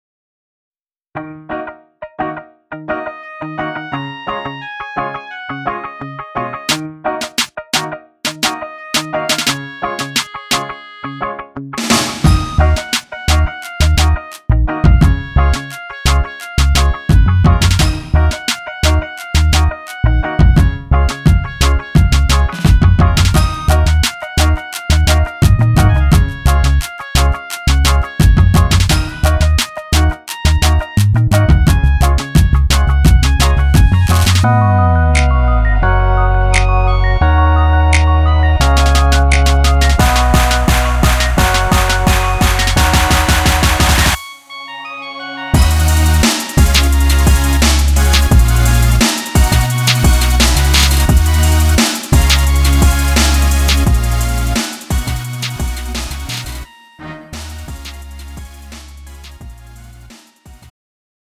미리듣기
음정 여자키 3:14
장르 가요 구분 Pro MR
Pro MR은 공연, 축가, 전문 커버 등에 적합한 고음질 반주입니다.